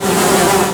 flies sound.
flies.wav